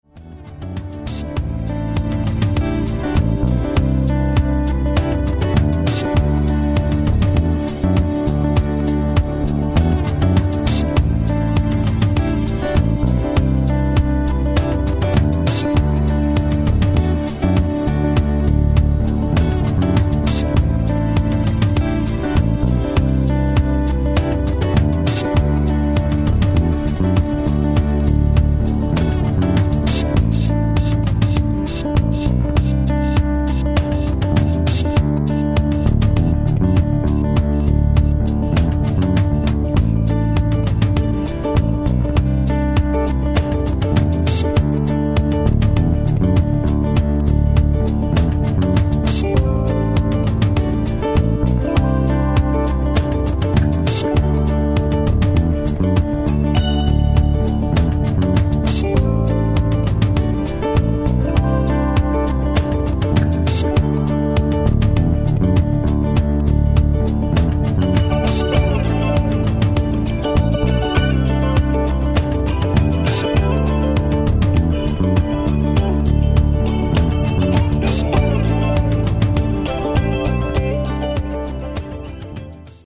dropping a hot piece of rocking dance music on wax